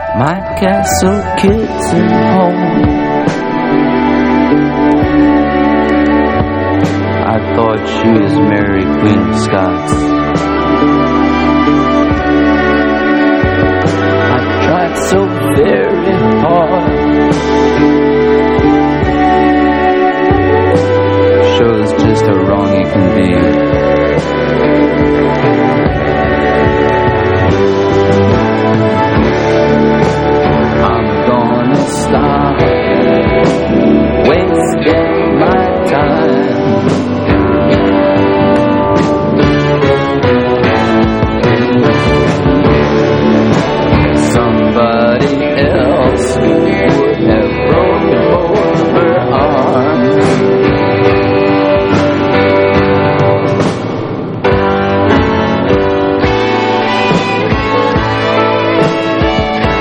ROCK / 70'S / SWAMP ROCK / PSYCHEDELIC ROCK / COUNTRY ROCK
71年サイケデリック風味ハード・カントリー・ロック唯一作！